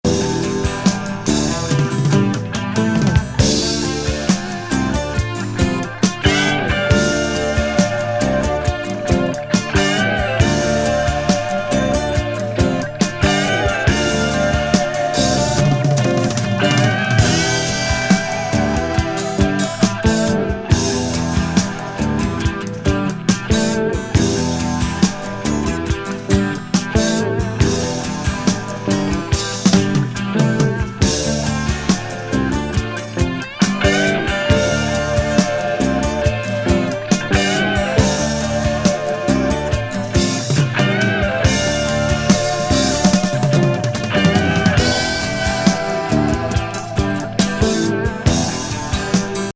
ジャズ・ファンク目白押し!